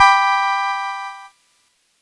Cheese Chord 13-G3.wav